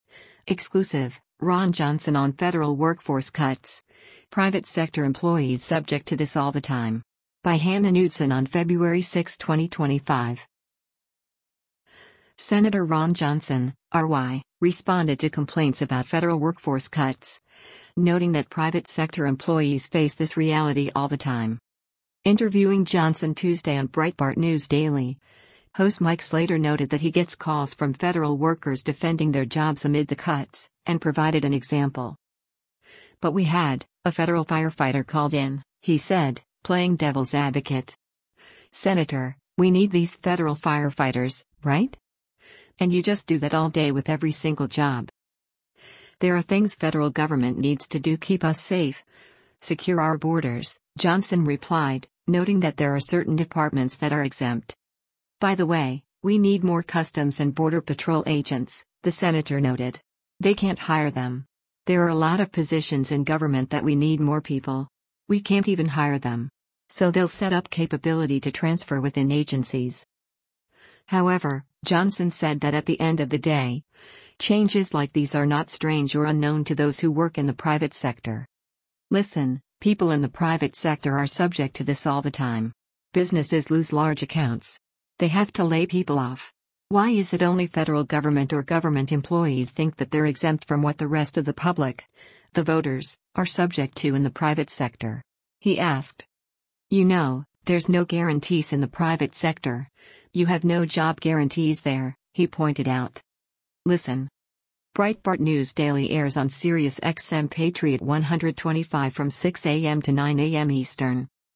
Interviewing Johnson Tuesday on Breitbart News Daily